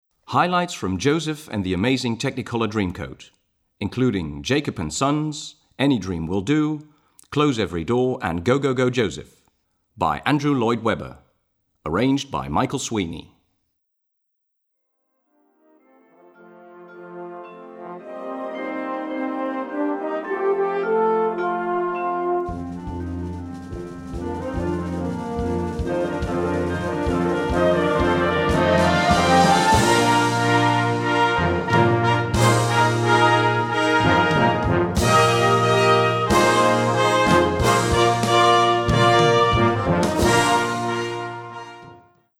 Gattung: Flex Band (5-stimmig)
Besetzung: Blasorchester